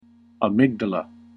/əˈmɪɡ.də.lə(英国英語), ˌæmɪˈgdɑlʌ(米国英語)/
フリガナアミグダラ